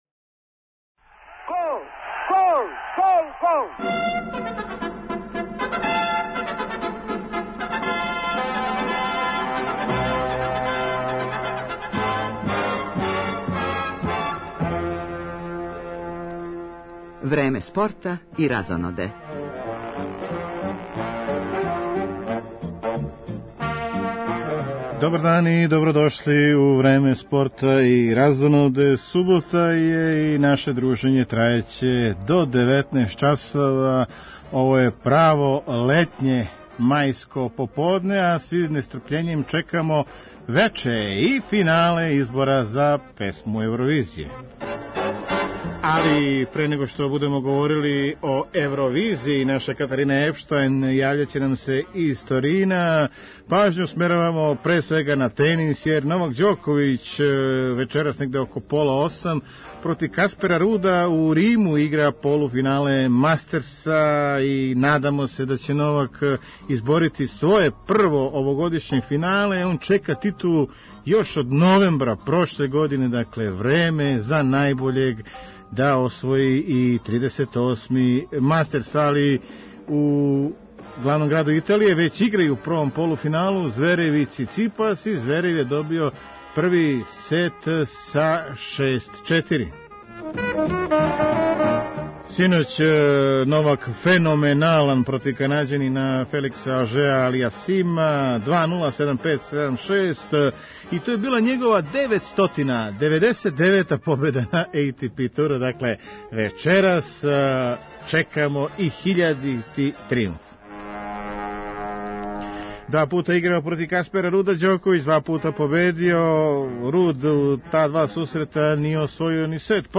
Када је реч о спорту у госте нам долазе представници 35-ог београдског маратона који се одржава сутра и стартоваће врло рано, већ у 8 сати.